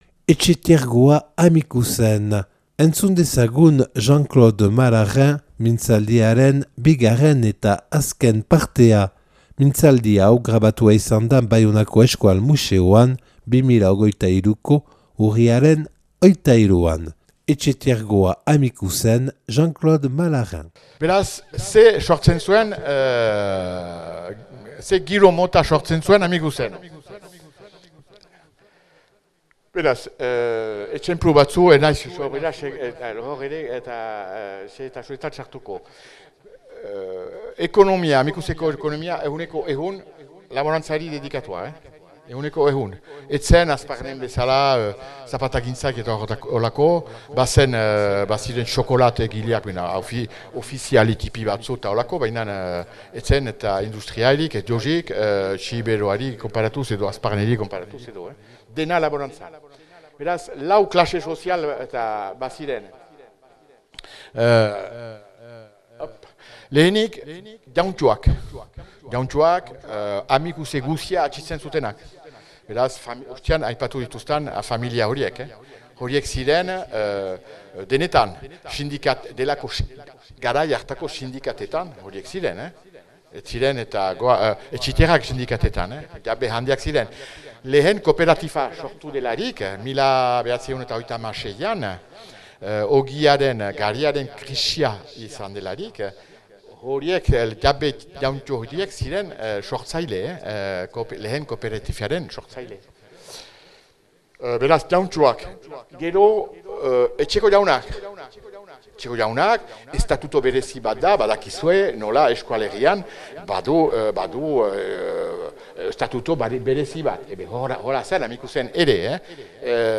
(Euskal Museoan grabatua 2023.